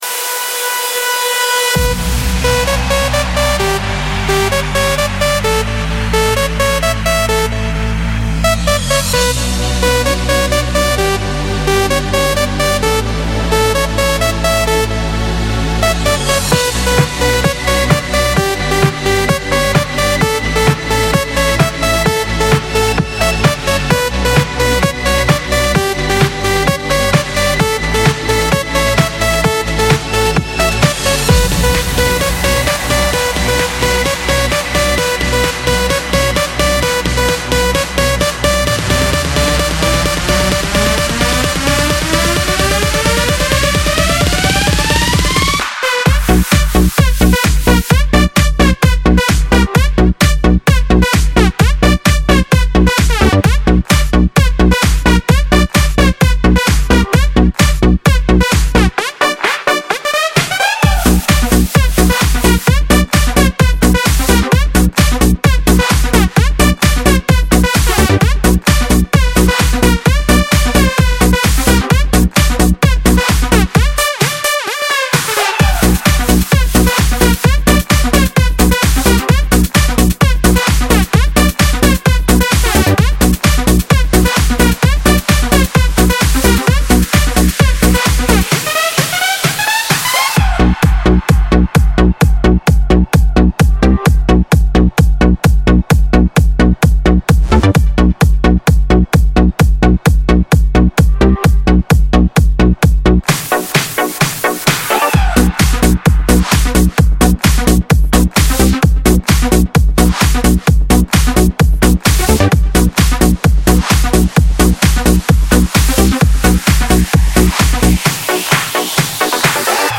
Категория: Клубняк | Добавил: Admin